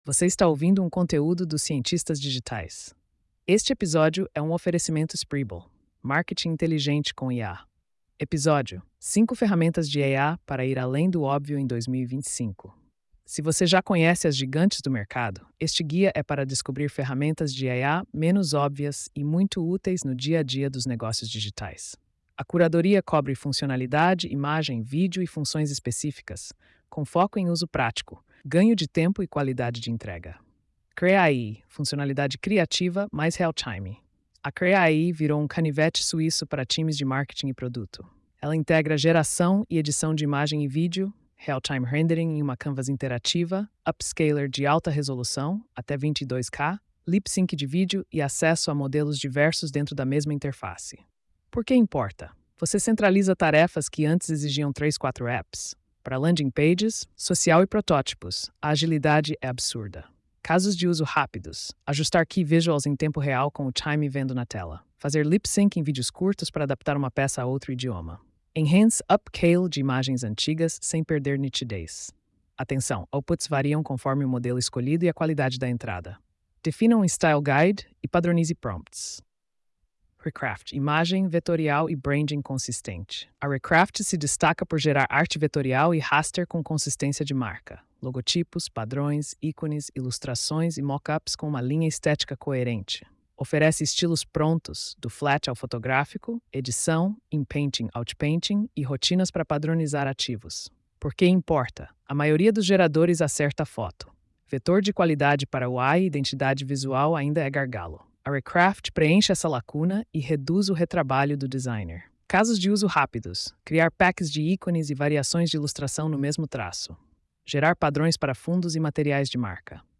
post-4342-tts.mp3